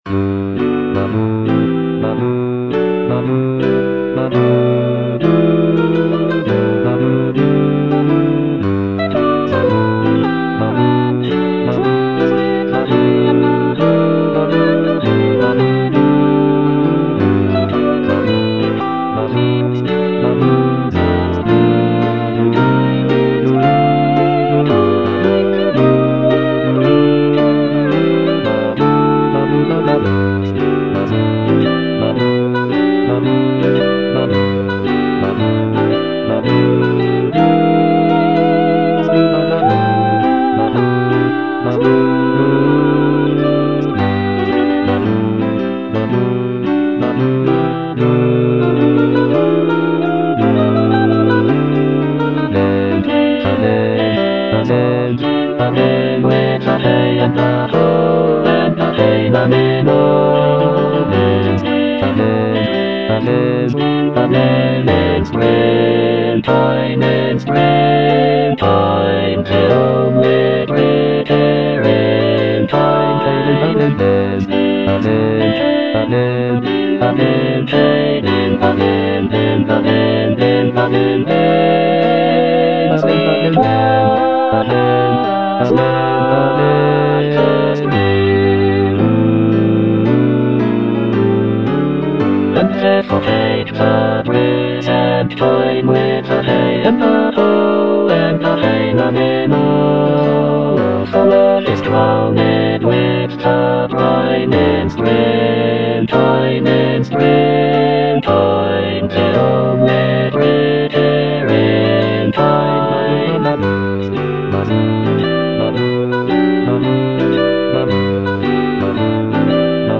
Bass (Emphasised voice and other voices)
choral work